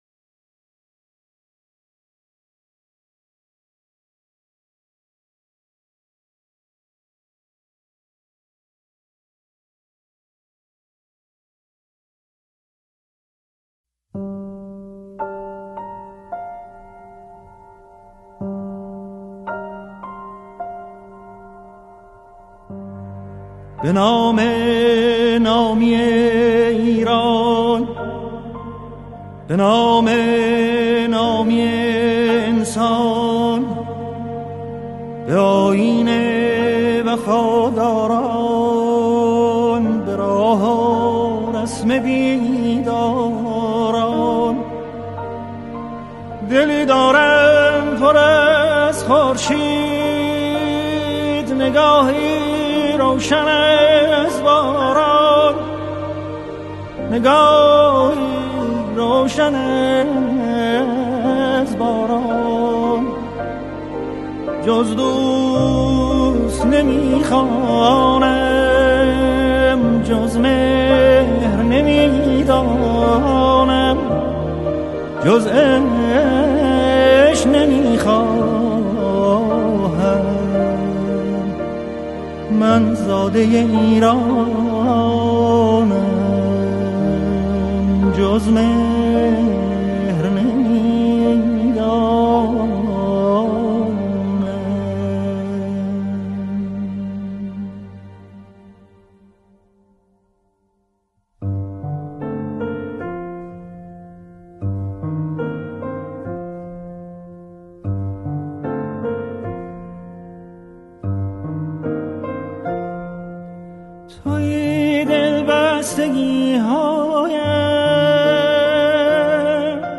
موزیک سنتی